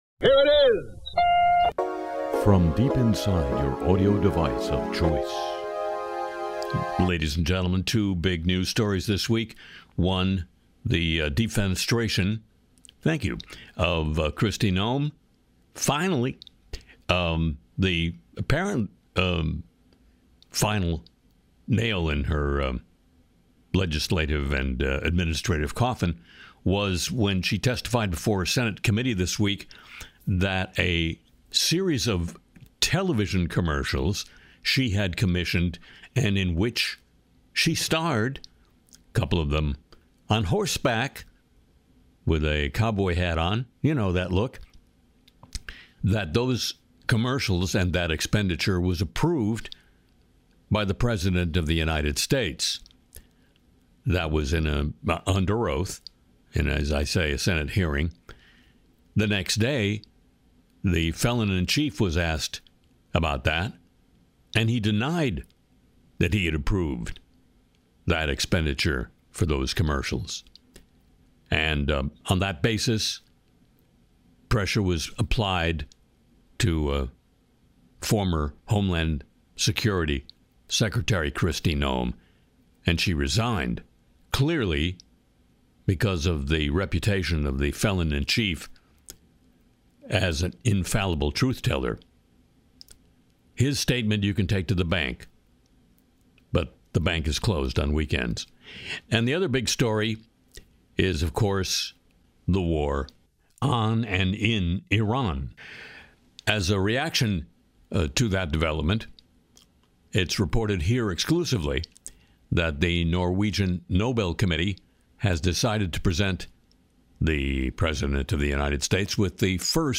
Harry Shearer’s Le Show (Mar 8 2026) features the Trump parody song 'My Feelings And My Gut,' Live News from CPR sketch, smart glasses spying.